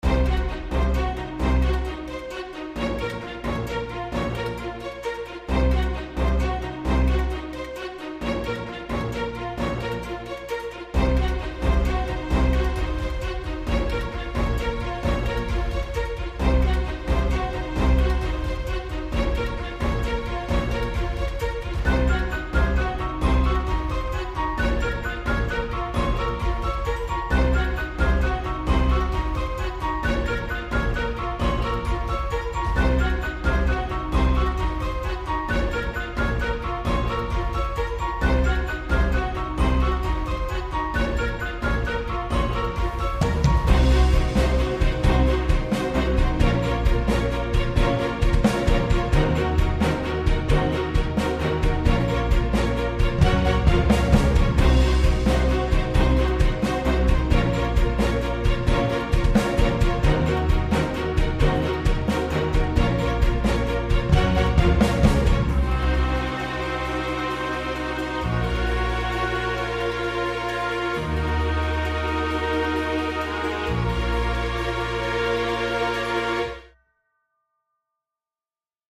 Genre: Orchestra
and using FL Studio with kontakt Libraries